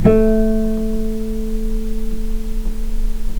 healing-soundscapes/Sound Banks/HSS_OP_Pack/Strings/cello/pizz/vc_pz-A3-pp.AIF at 61d9fc336c23f962a4879a825ef13e8dd23a4d25
vc_pz-A3-pp.AIF